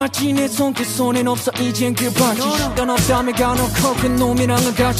DBM_RF2_82_Guitar_Fx_OneShot_Apologies_Emin